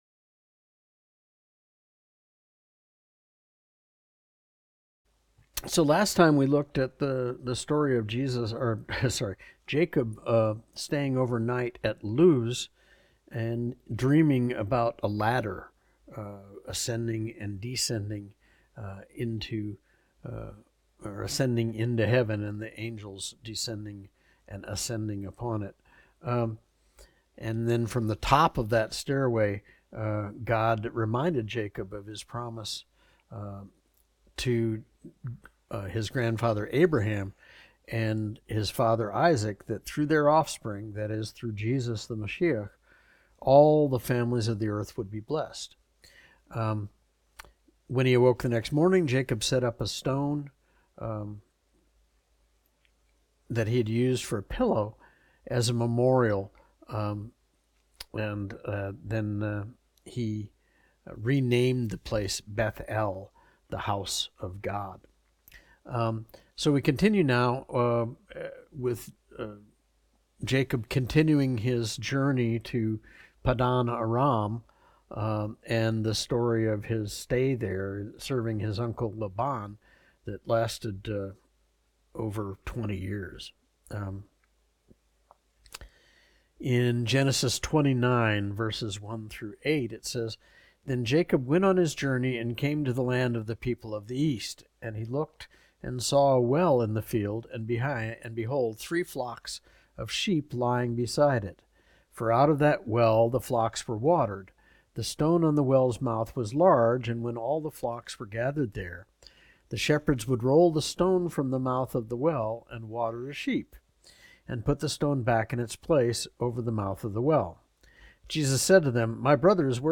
Study Type - Adult Lesson